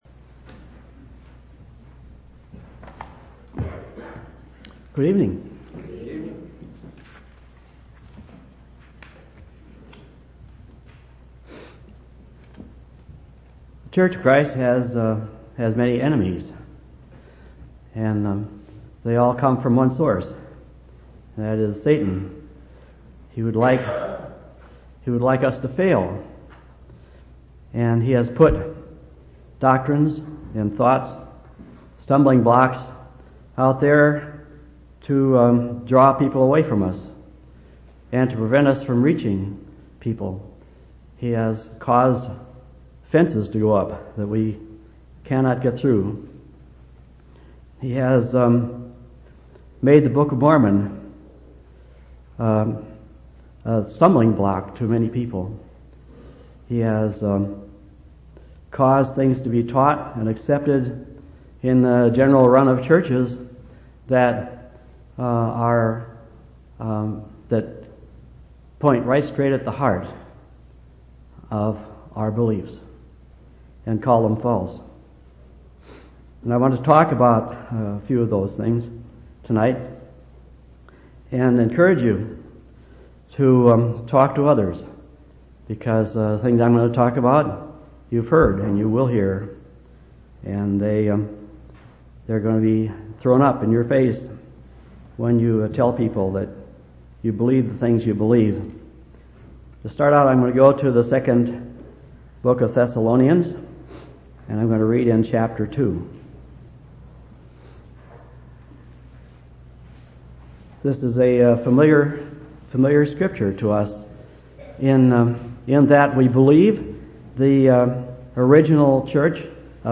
1/11/2004 Location: Temple Lot Local Event